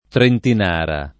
[ trentin # ra ]